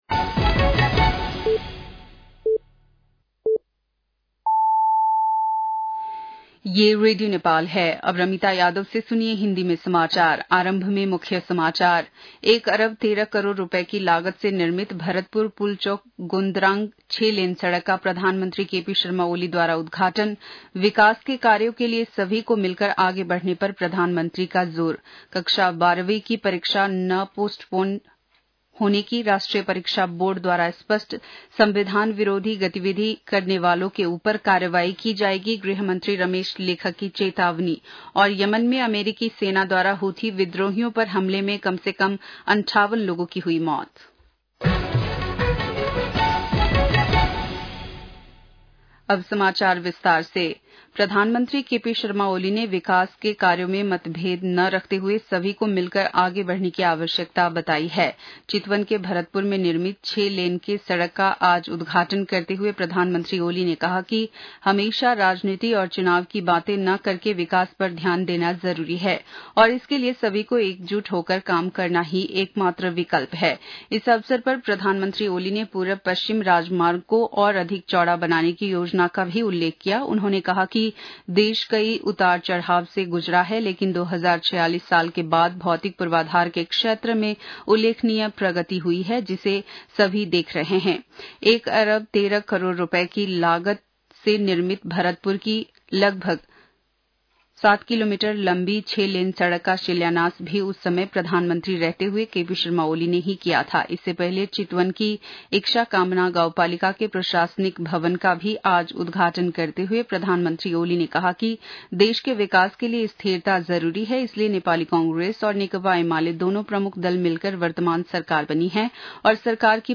बेलुकी १० बजेको हिन्दी समाचार : ५ वैशाख , २०८२
10-pm-hindi-news-1-05.mp3